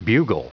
Prononciation du mot bugle en anglais (fichier audio)
Prononciation du mot : bugle